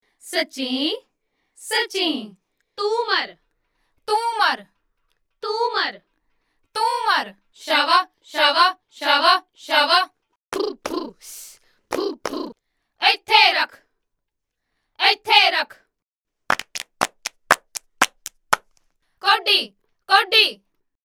Female Vocals | Amit Sharma Productions
160 Punjabi Backing vocal loops
Professionally recorded samples at various Key and Bpm